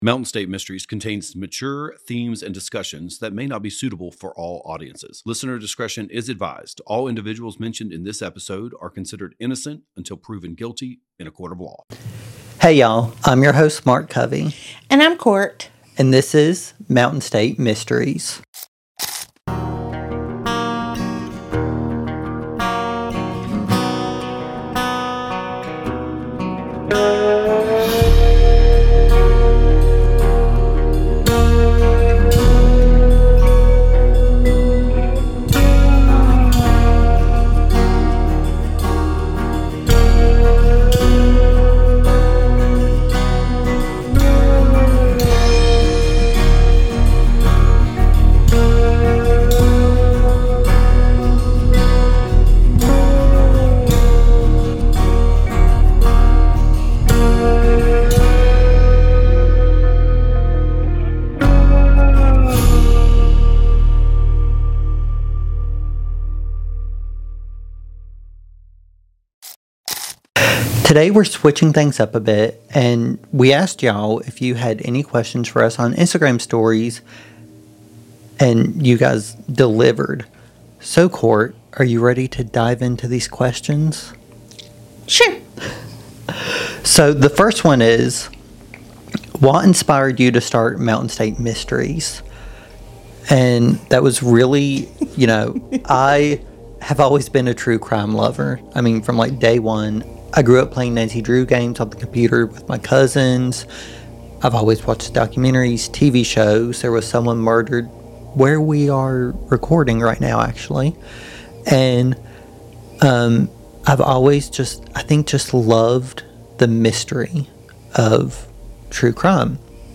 A West Virginia True Crime Podcast